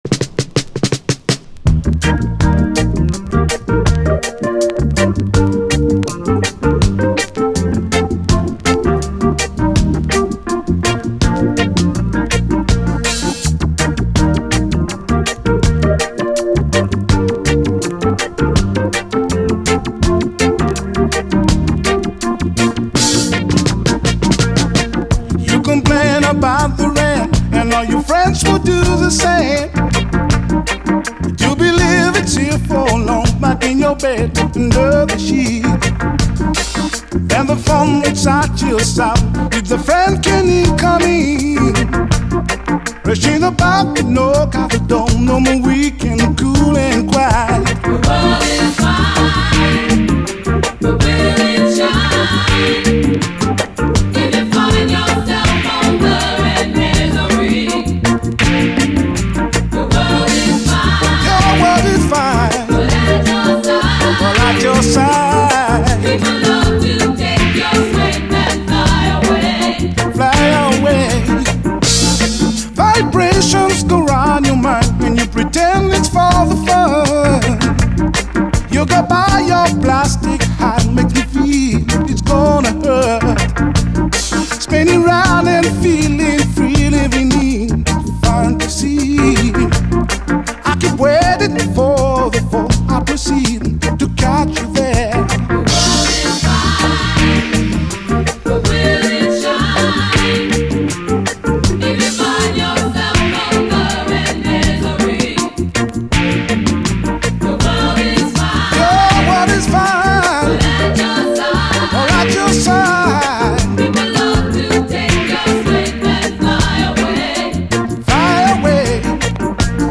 REGGAE, 7INCH
トボけたシンセ使いのフレンチ・トロピカル・レゲエ！